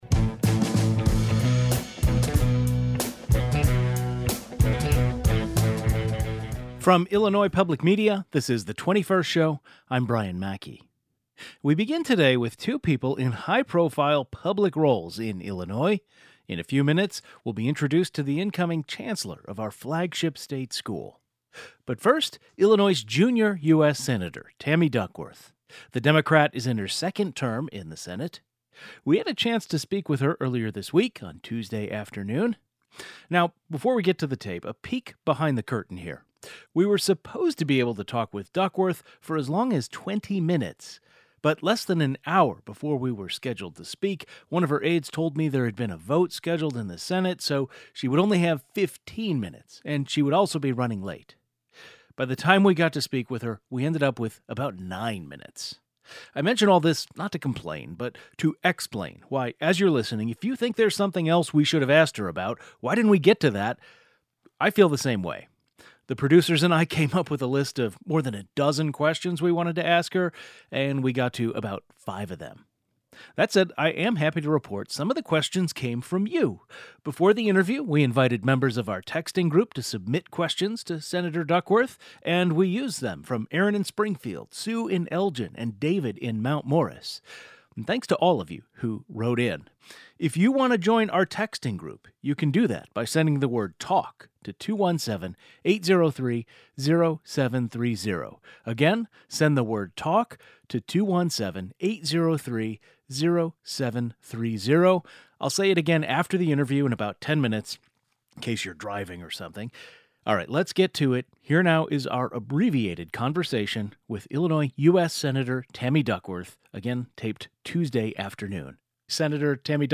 GUEST Tammy Duckworth U.S. Senator, D-Illinois Tags